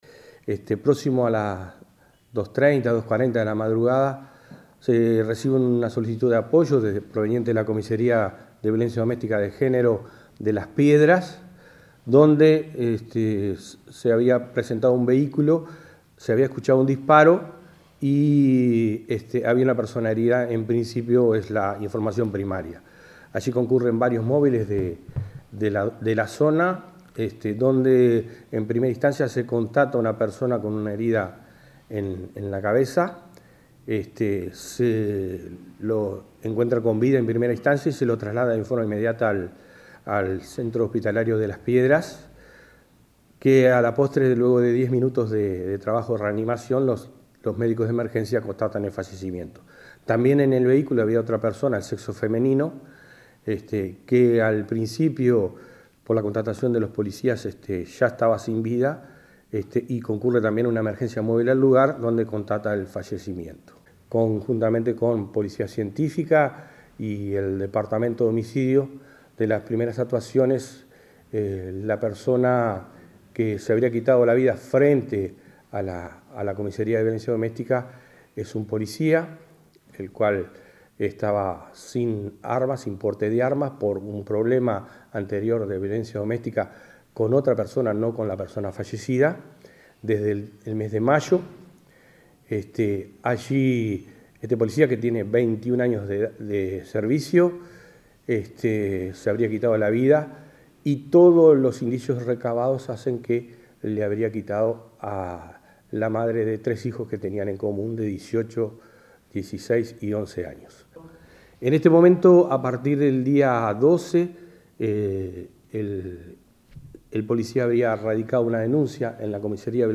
Escuchar al jefe de Policía